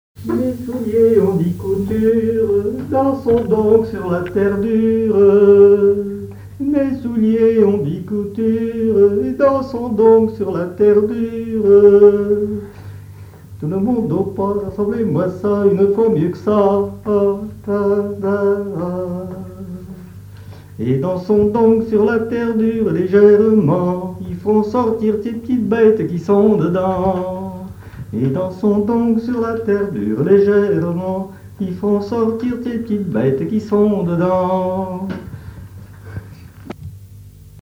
Garnache (La) ( Plus d'informations sur Wikipedia ) Vendée
danse : ronde : grand'danse
Genre énumérative
accordéon chromatique